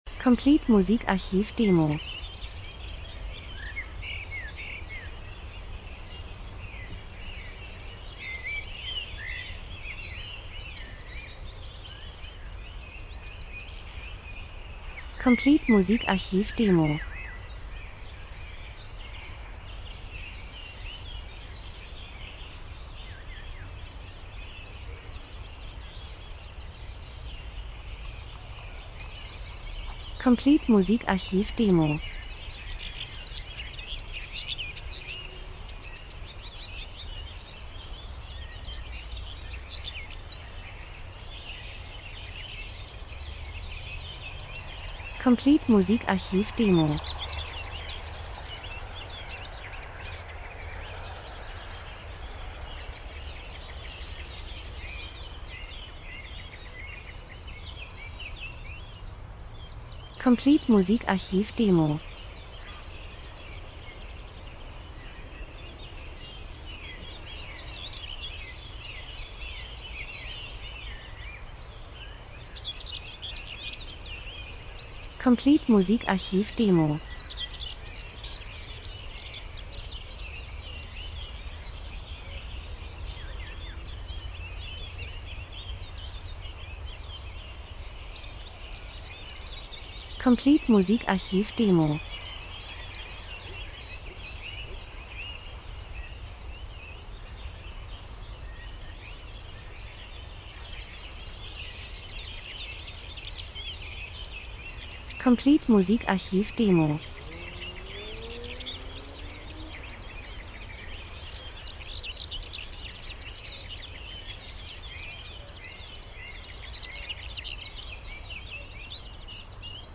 Sommer -Geräusche Soundeffekt Natur Wald Vögel 03:01